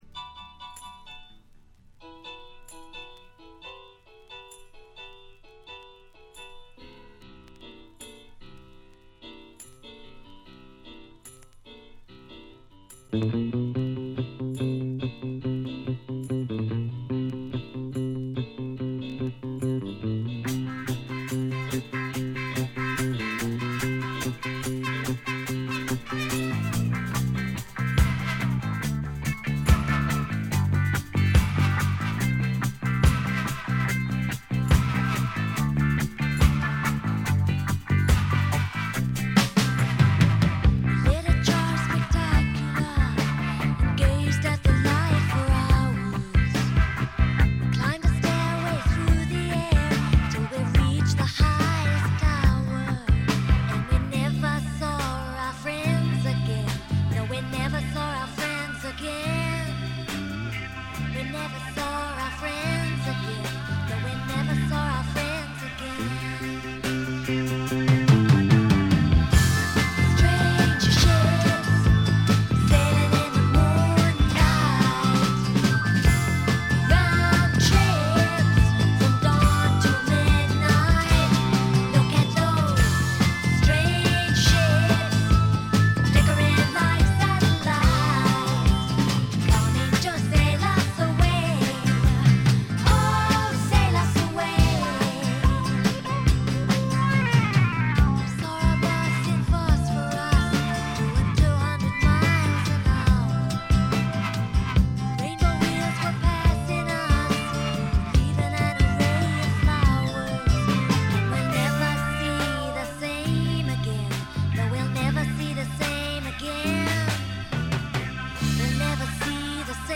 ごくわずかなノイズ感のみ。
試聴曲は現品からの取り込み音源です。
Lead Vocals